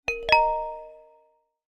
beep2.mp3